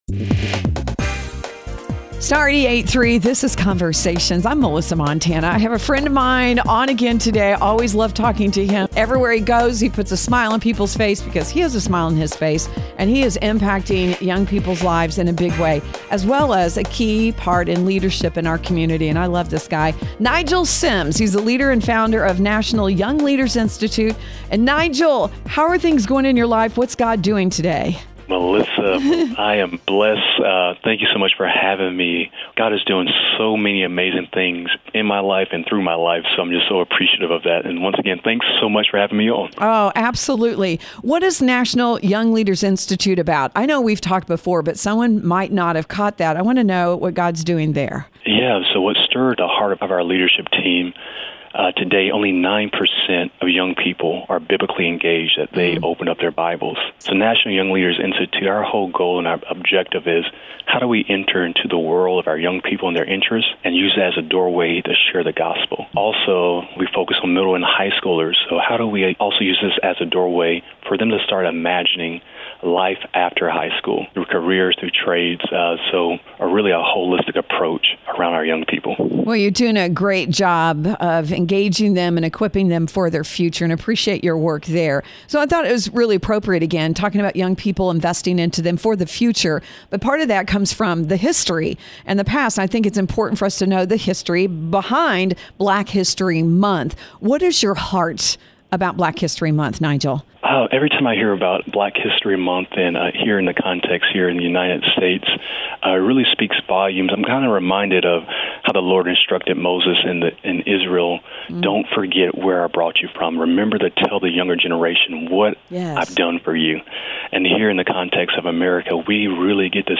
For other interviews covering several different topics heard on Conversations